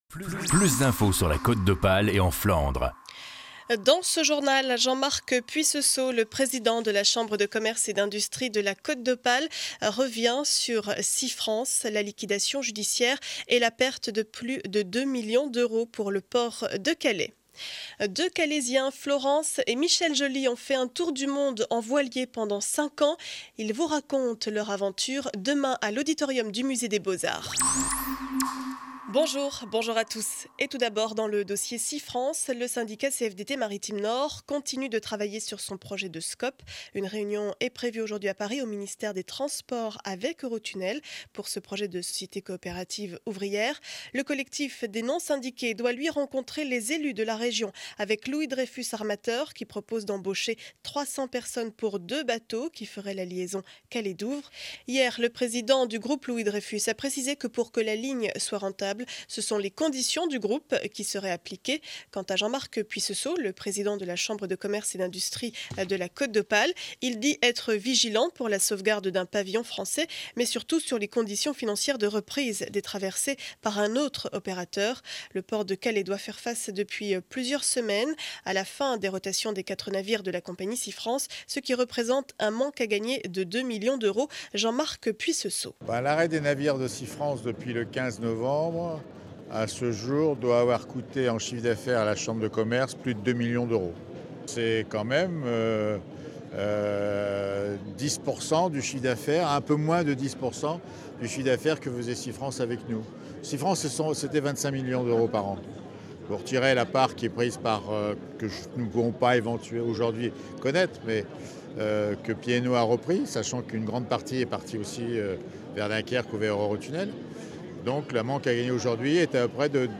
Journal du jeudi 12 janvier 7 heures 30 édition du Calaisis.